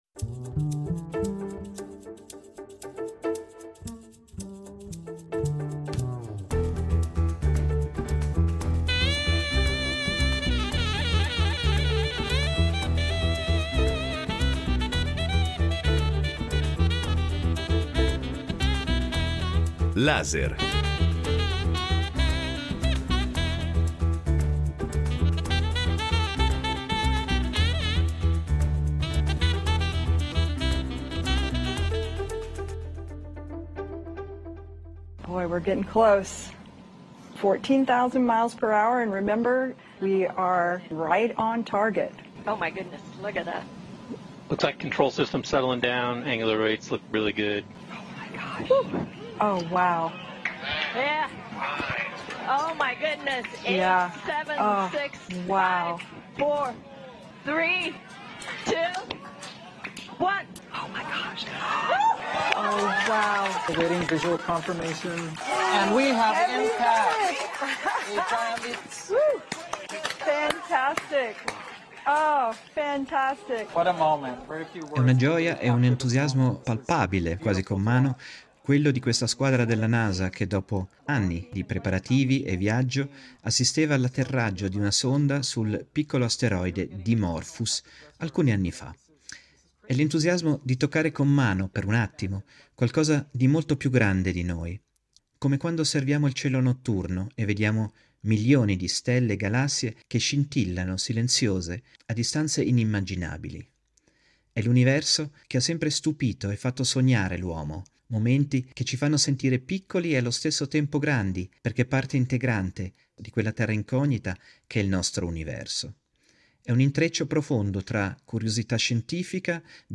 Ai confini dell’universo. Incontro con l’astrofisica